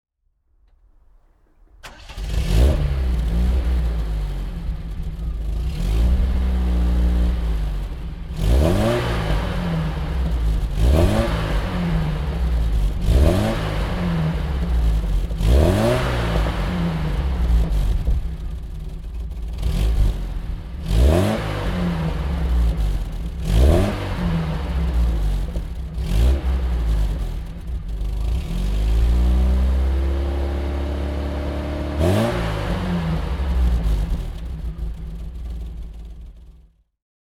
Triumph TR 7 (1980) - Starten und Leerlauf
Triumph_TR_7_1980.mp3